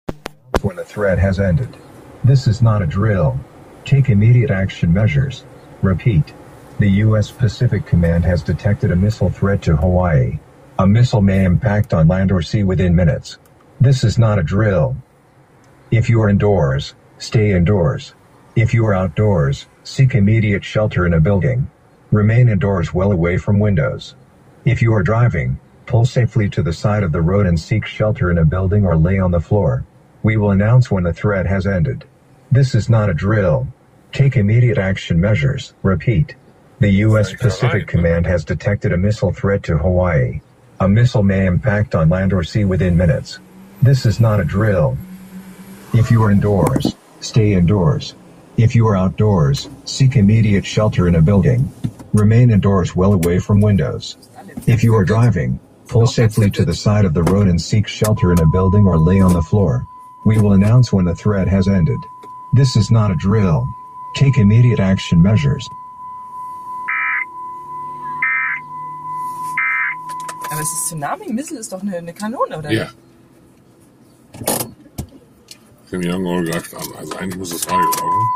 Im Radio kommt das bereits bekannte tröten, das eine offizielle Warnung ankündigt, das muss die Tsunami Test-Meldung sein, aber der Tag und die Uhrzeit passen nicht.
misslealert_kurz.mp3